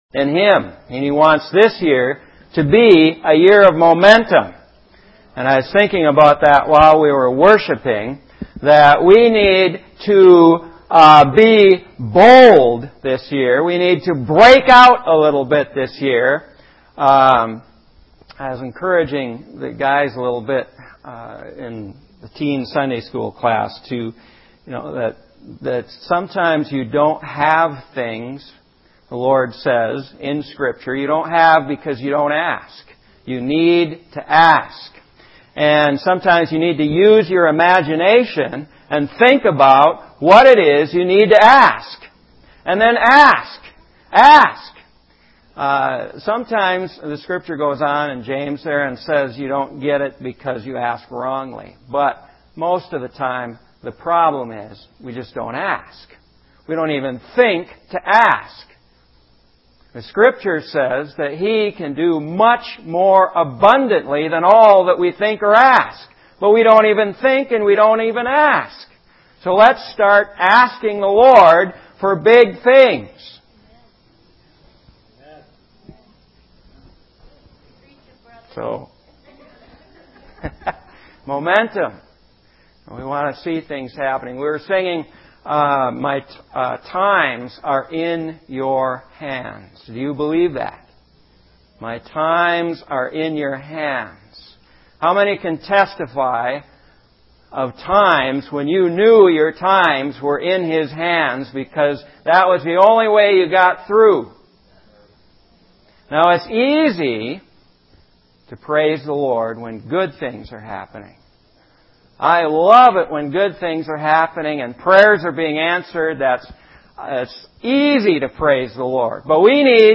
Too often we get the gospel backwards! 120115-KindnessOfGod This entry was posted in sermons .